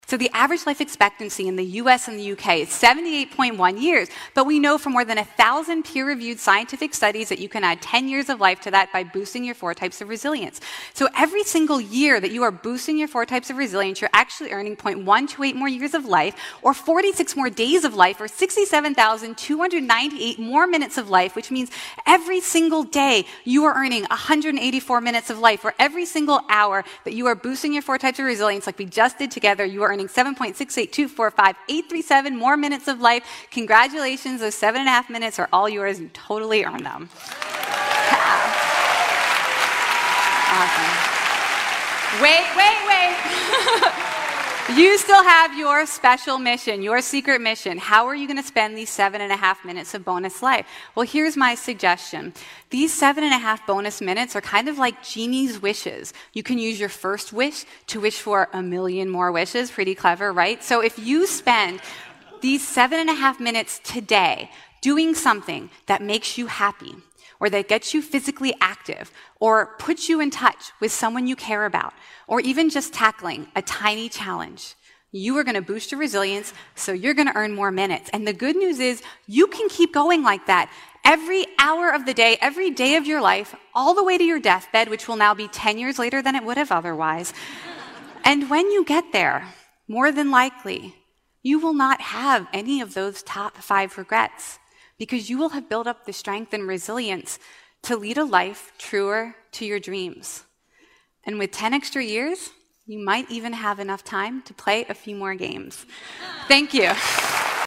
TED演讲：能让你多出10年额外寿命的游戏(11) 听力文件下载—在线英语听力室